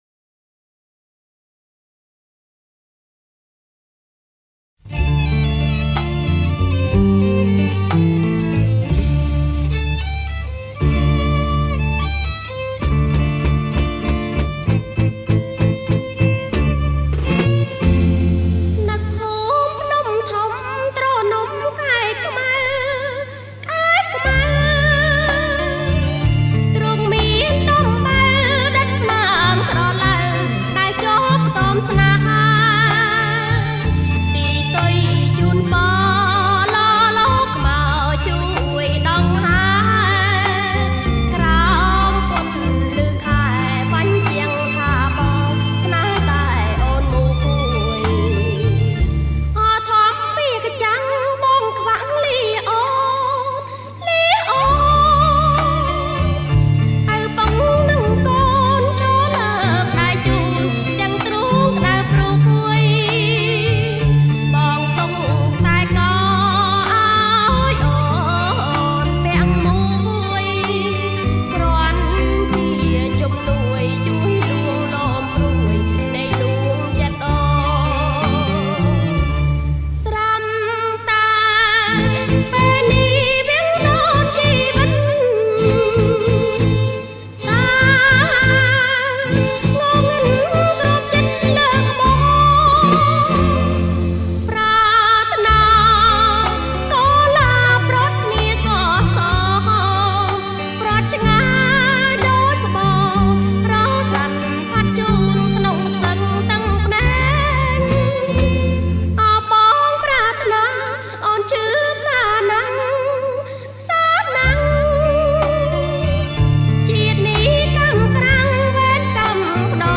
ប្រគំជាចង្វាក់  Slow Rock